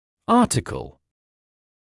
[‘ɑːtɪkl] [‘аːтакл] стать (в журнале, период.издании)